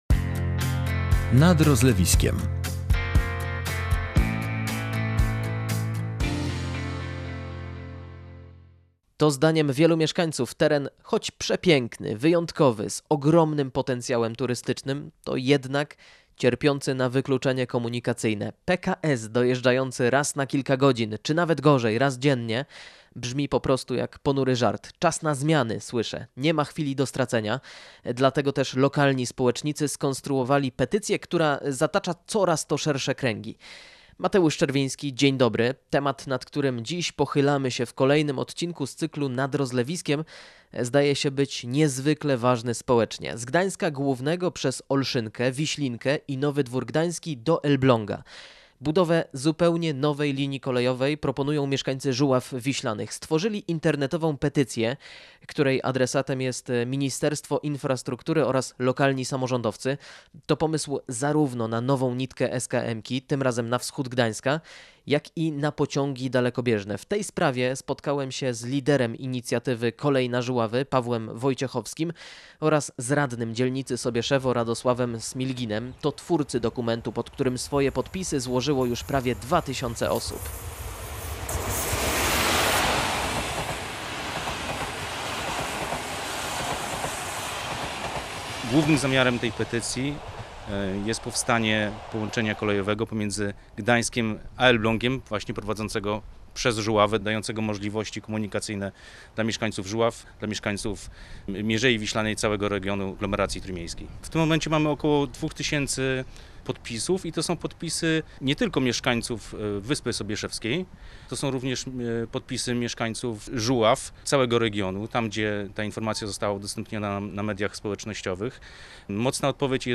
Nie ma chwili do stracenia – słyszymy na miejscu.